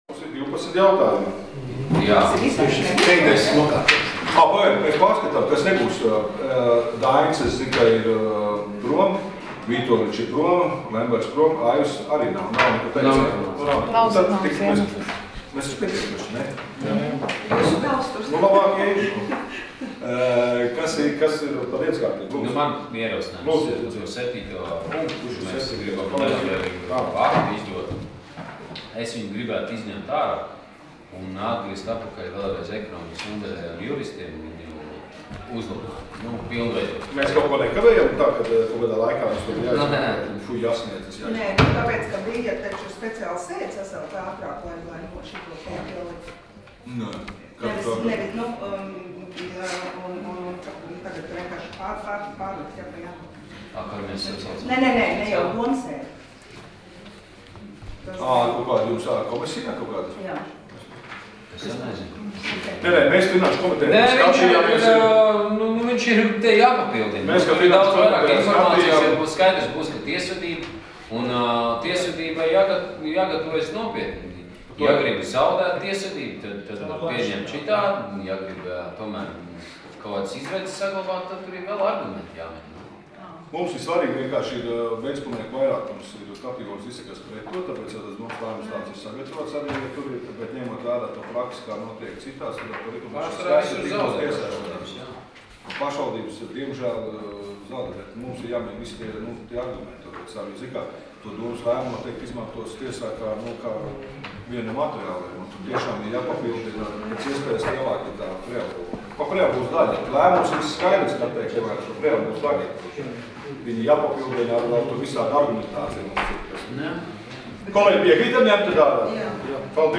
Domes sēdes 08.04.2016. audioieraksts